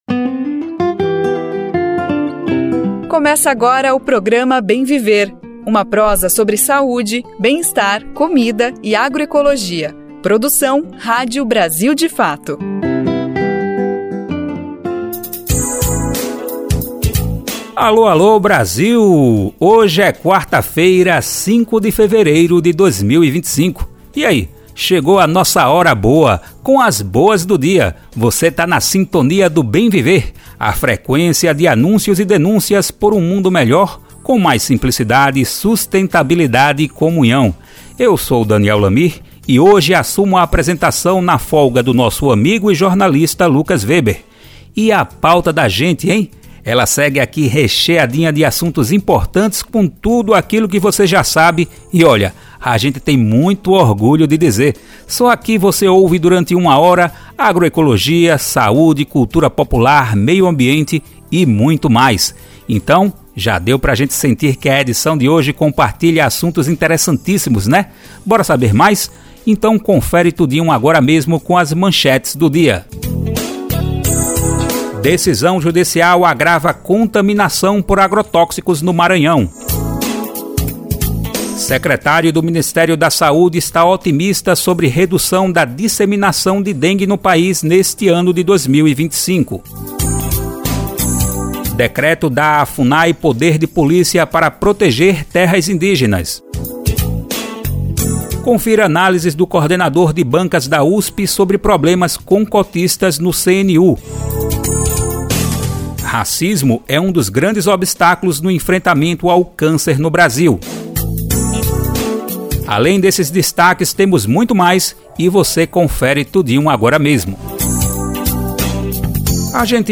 Na entrevista, a ex-ministra avalia a gestão de sua sucessora, Anielle Franco , e fala dos desafios para o país implementar a lei que garante o ensino de história afro-brasileira nas escolas.